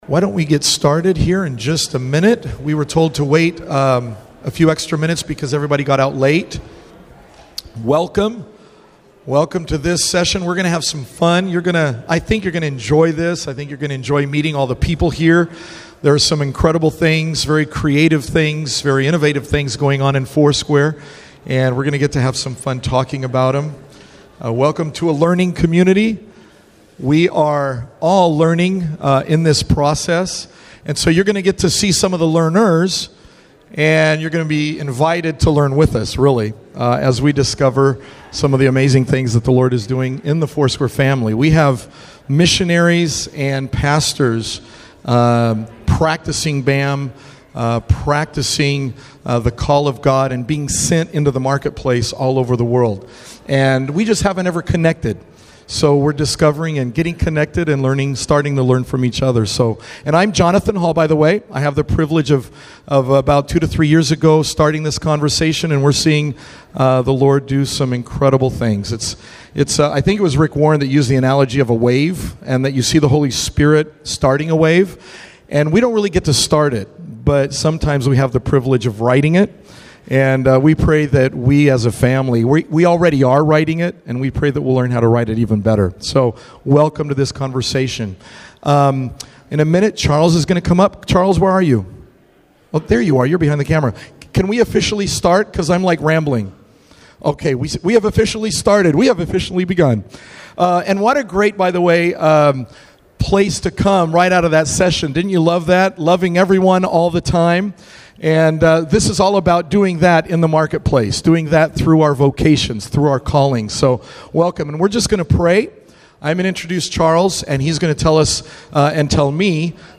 Workshop: Sent to do business - News + Resources